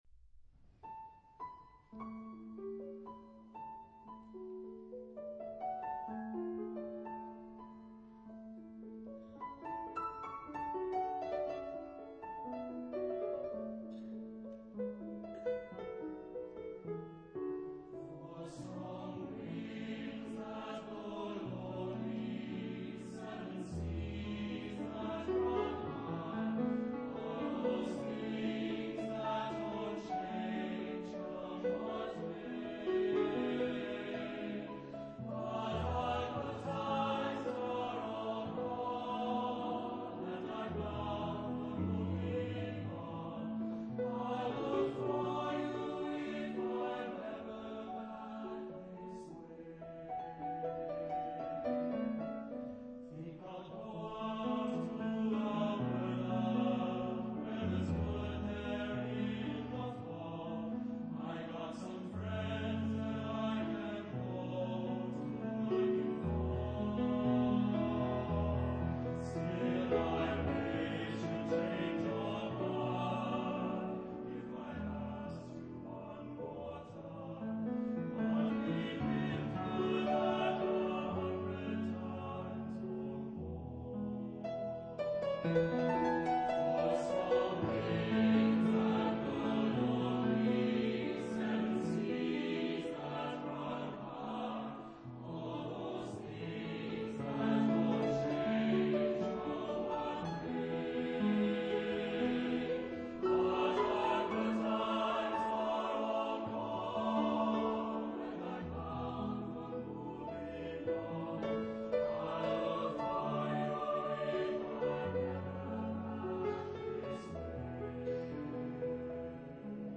Genre-Stil-Form: weltlich ; Abschiedslied
Charakter des Stückes: melancholisch
Chorgattung: SATB  (4-stimmiger gemischter Chor )
Instrumentation: Klavier  (1 Instrumentalstimme(n))
Tonart(en): As-Dur